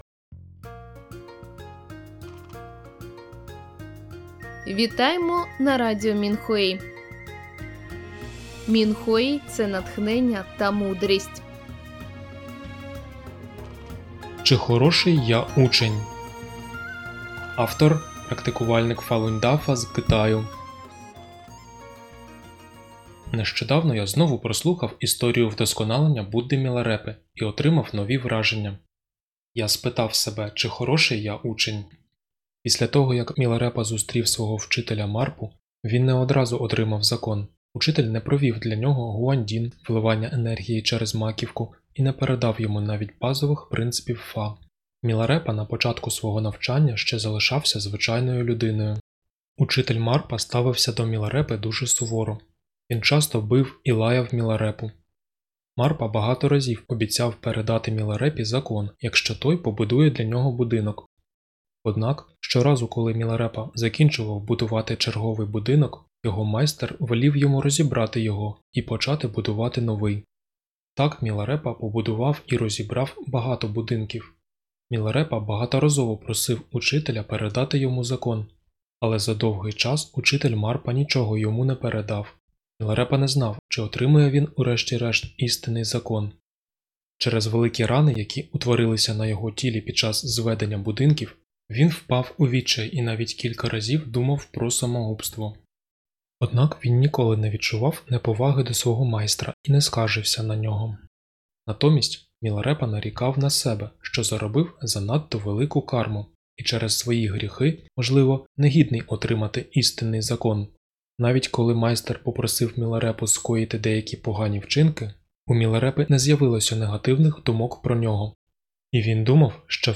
Музику з подкастів написали й виконали учні Фалунь Дафа (Фалуньгун).